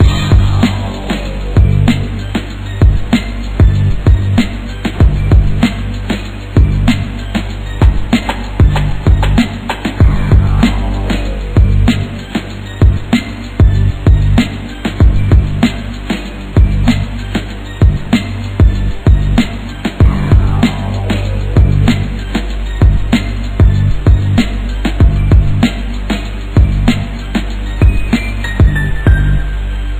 саундтреки
без слов
instrumental hip-hop
beats